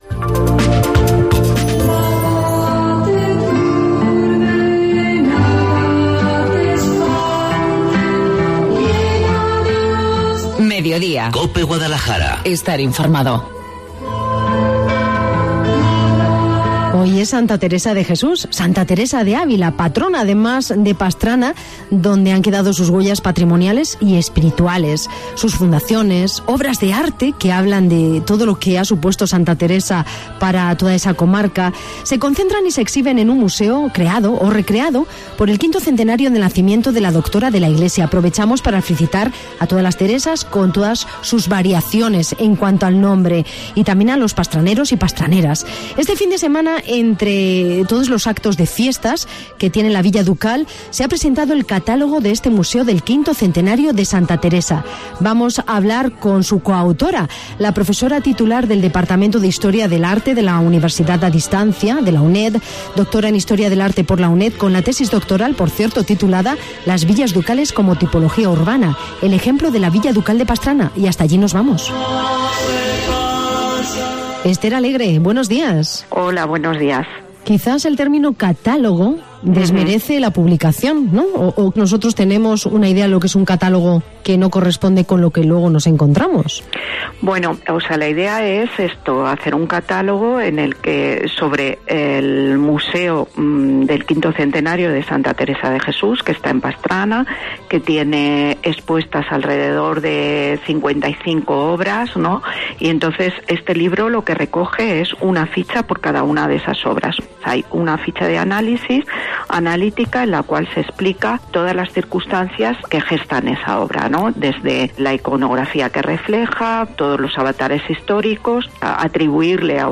En el día de Santa Teresa de Jesús hacemos una visita guiada por este Museo de la Villa Ducal